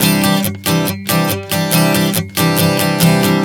Strum 140 C 04.wav